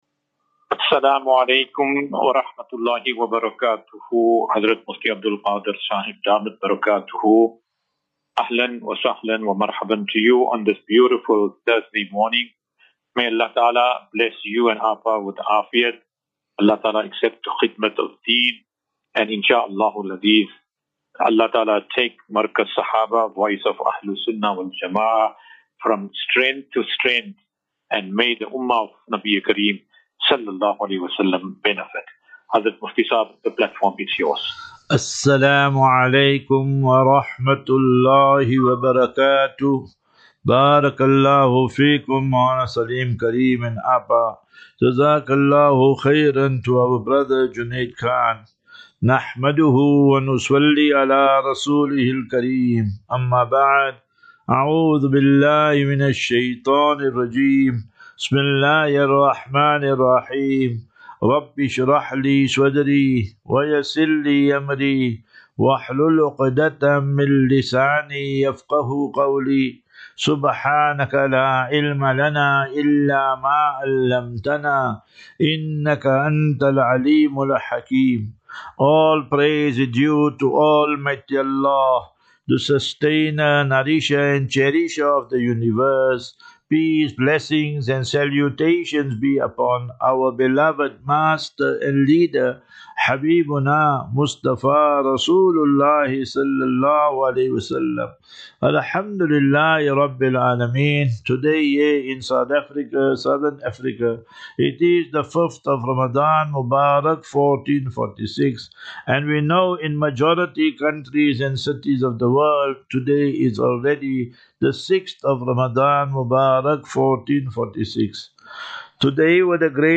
As Safinatu Ilal Jannah Naseeha and Q and A 6 Mar 06 March 2025.